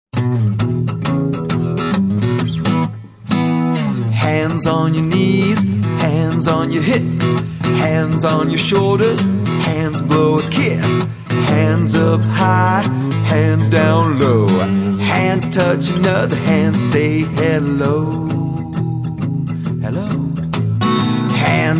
Positive Behavior Song for Children